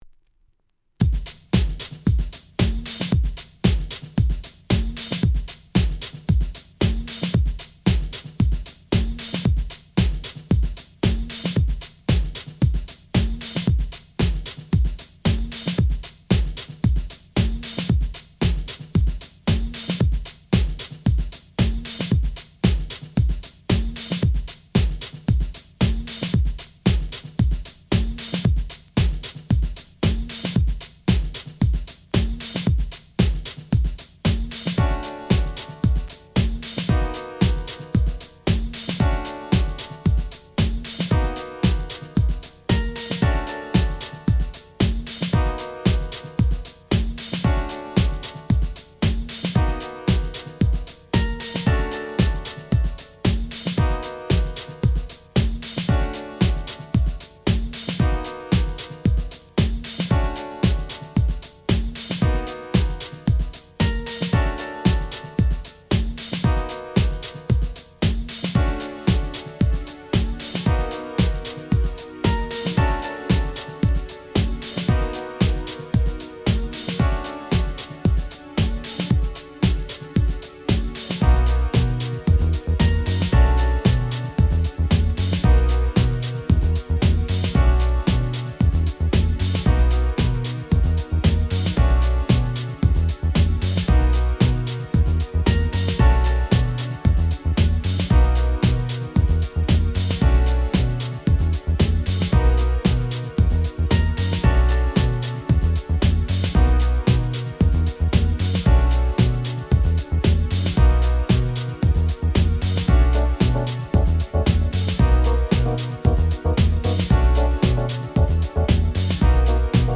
Genre: Rap.